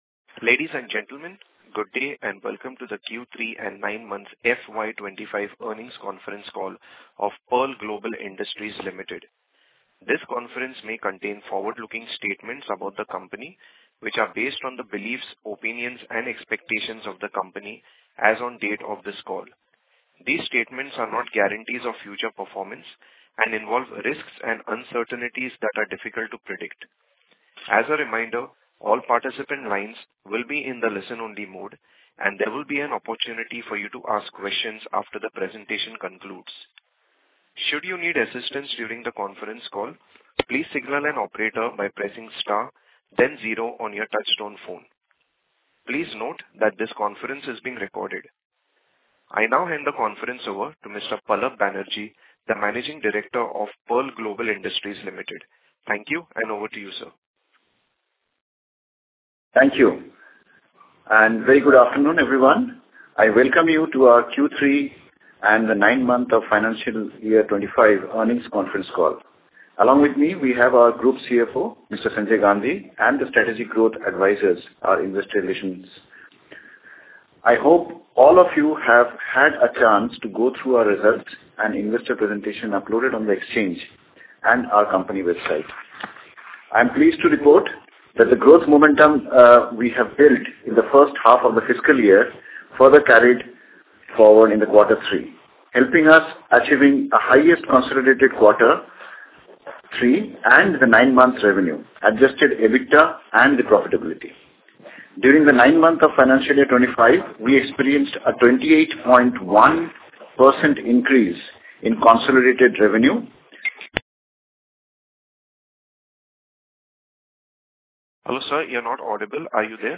Concalls
Pearl-Global-Earnings-call-audio-Q32025.mp3